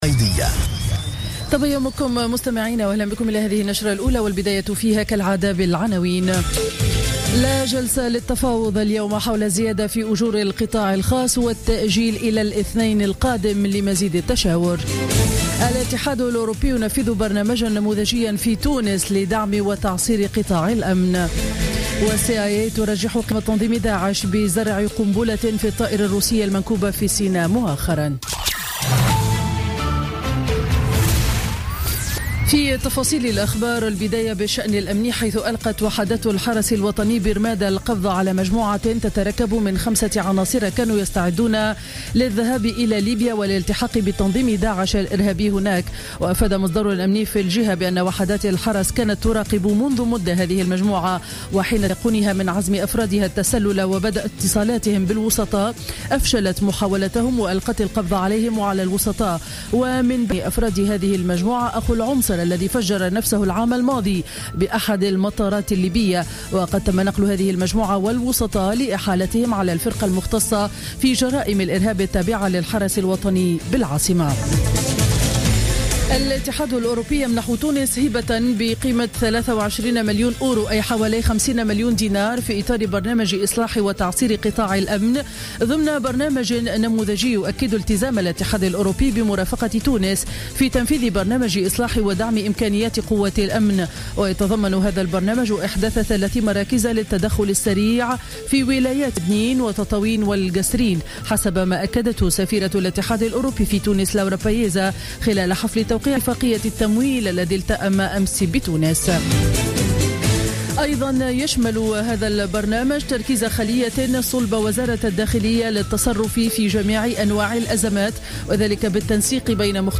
نشرة أخبار السابعة صباحا ليوم الخميس 5 نوفمبر 2015